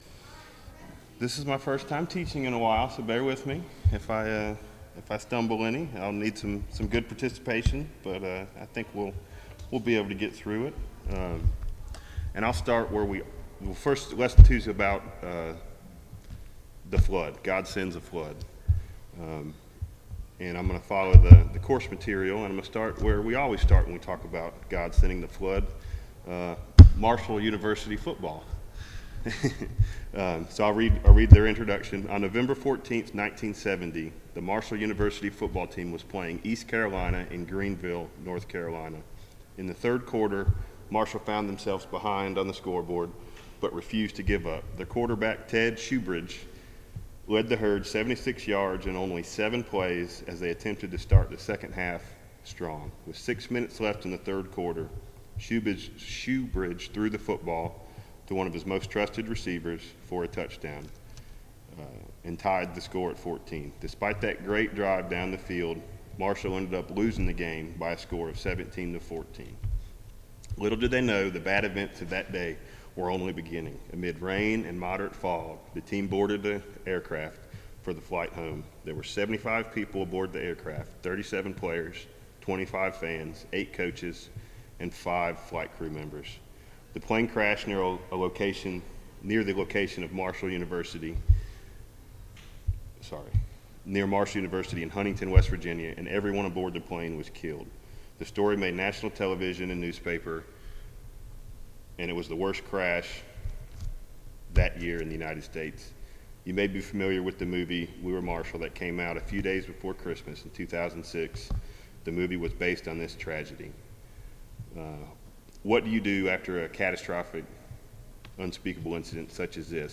Genesis 7 Service Type: VBS Adult Class Topics